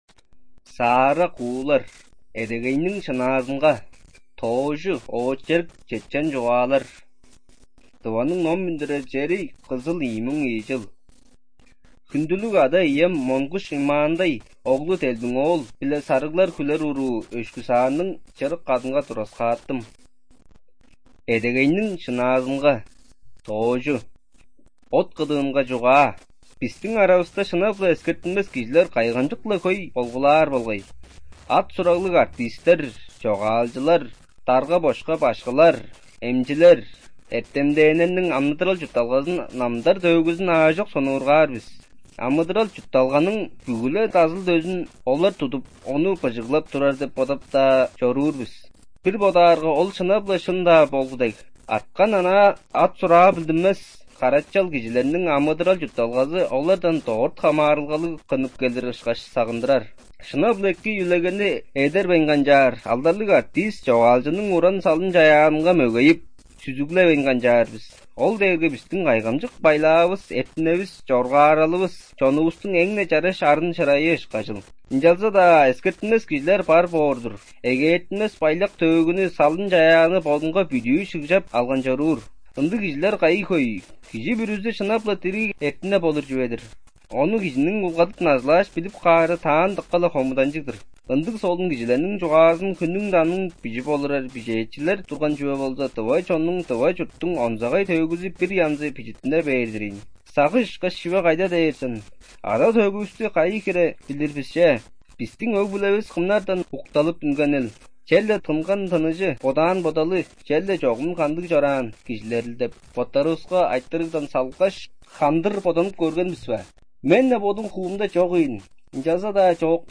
Студия звукозаписиТувинская республиканская специальная библиотека для незрячих и слабовидящих